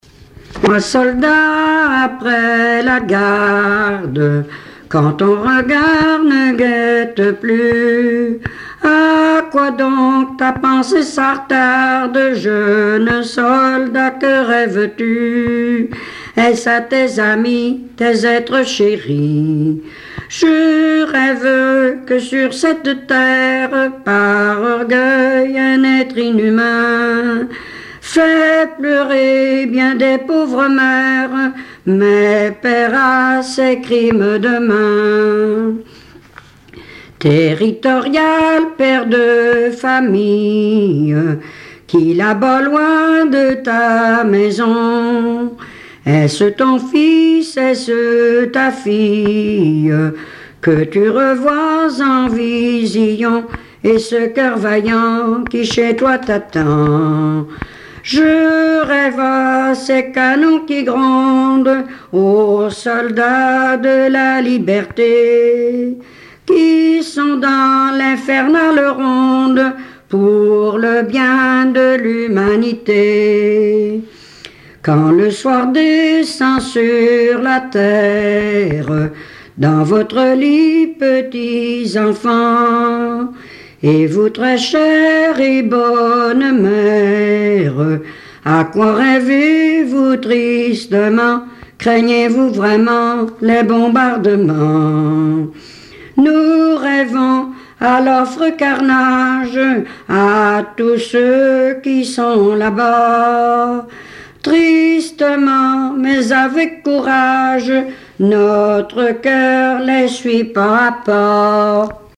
Chansons traditionnelles et populaires
Pièce musicale inédite